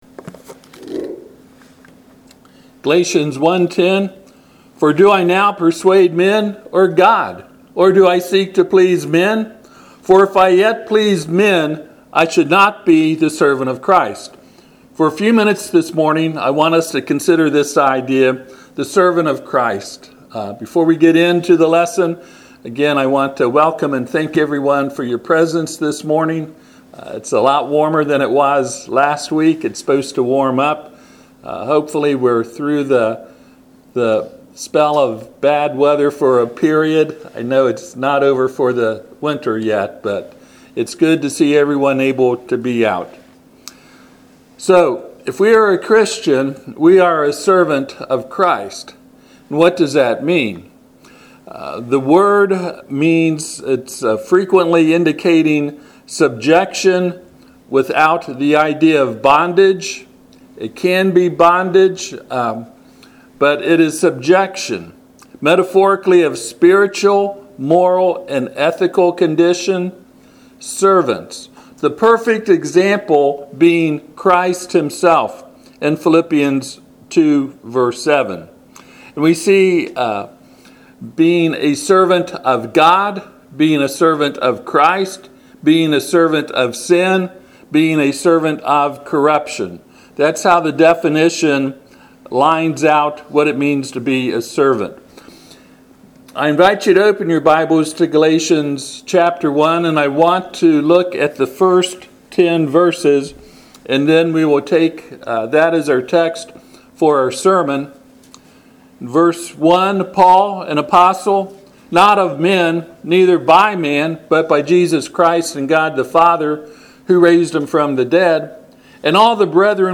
Galatians 1:10 Service Type: Sunday AM https